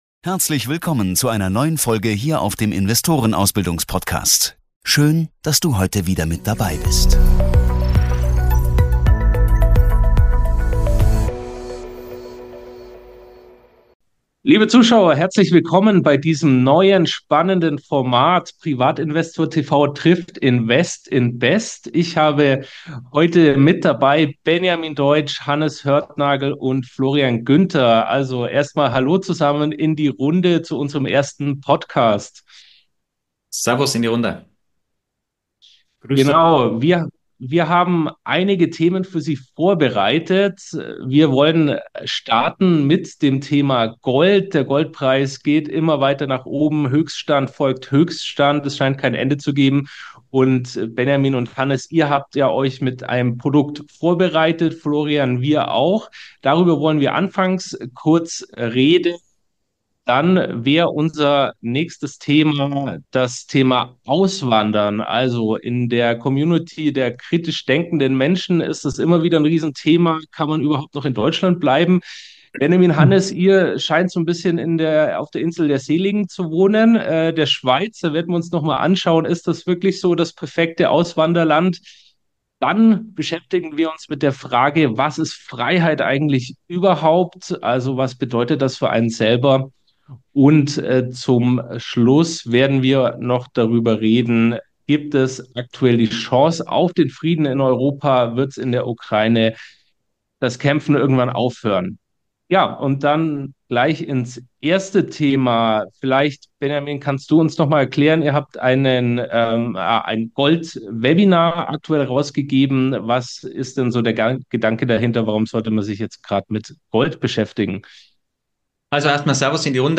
Gold auf Rekordhoch, globale Konflikte und die Frage nach wahrer Freiheit – ein spannendes Roundtable-Gespräch mit Top-Experten!